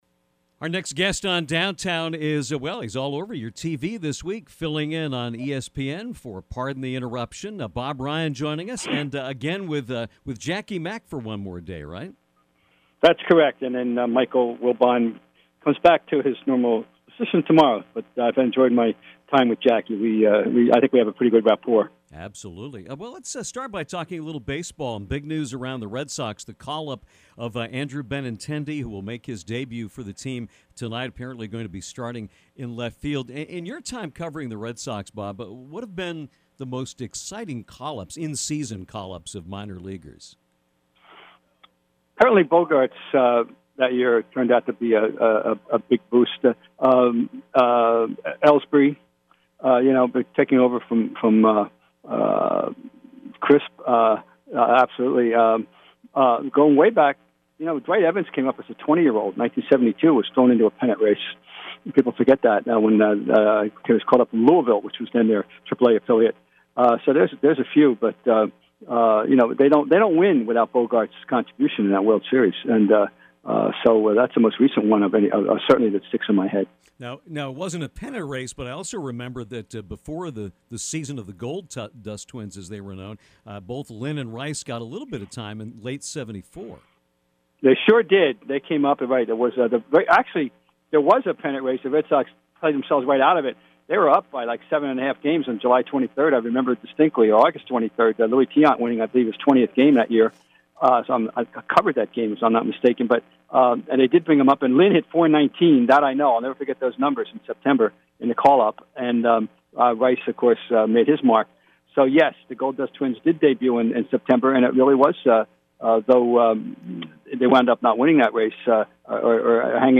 The quintessential American sports writer, Bob Ryan joined the Downtown show on Wednesday afternoon and ran the gauntlet of topics discussed, touching on the current Red Sox, memorable call-ups while […]
There are few people who are as knowledgeable on so many subjects; check out this fascinating conversation with an American legend. https